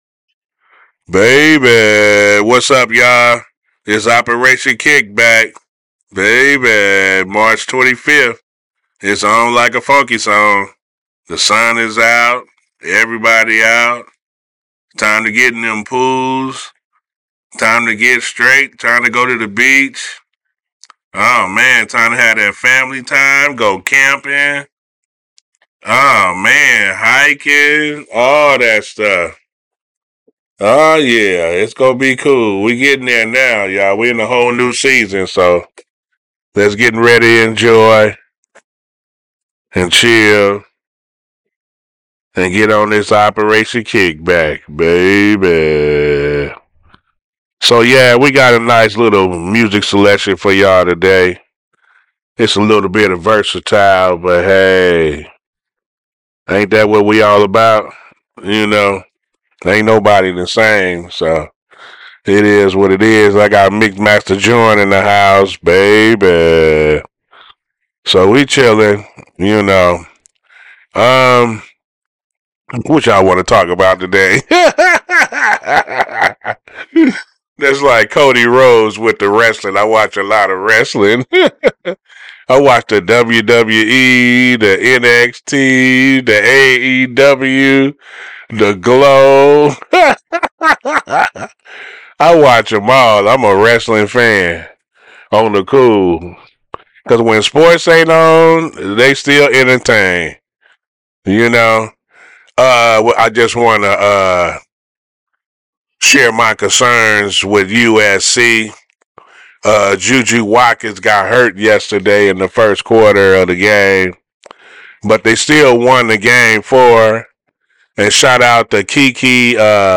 This episode of Voices from THE HUB FT Moment In Between and Operation Kickback aired live on CityHeART Radio on Tuesday Mar. 25 at 10am.
Moment in Between also shares the airwaves with Operation Kickback – with another exciting show from them full of awesome music for this episode!